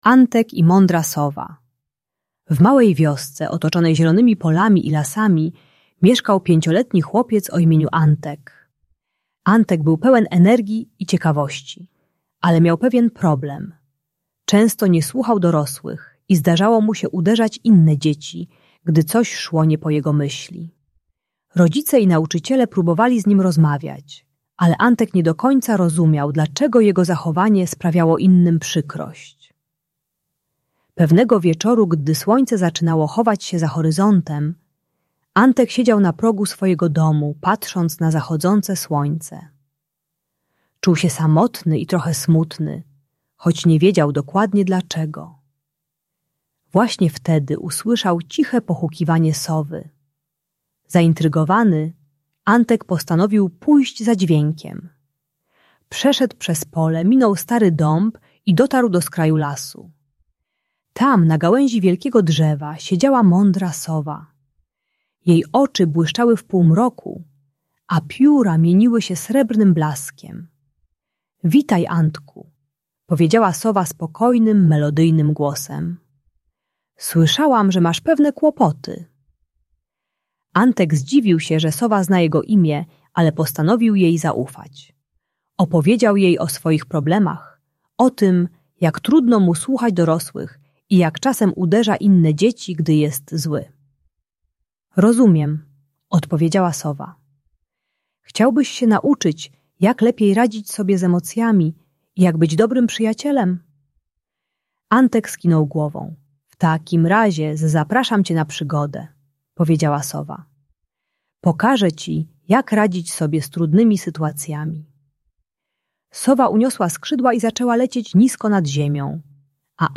Historia Antka i Mądrej Sowy - Bunt i wybuchy złości | Audiobajka